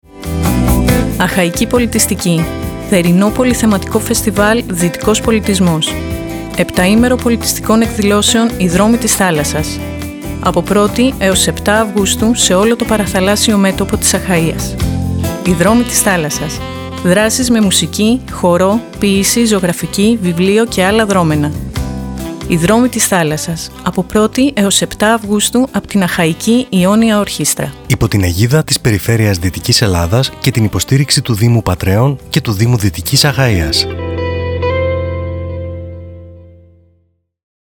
ΑΧΑΙΚΗ ΠΟΛΙΤΙΣΤΙΚΗ – ΝΕΟ ΡΑΔΙΟΦΩΝΙΚΟ ΣΠΟΤ “ΟΙ ΔΡΟΜΟΙ ΤΗΣ ΘΑΛΑΣΣΑΣ “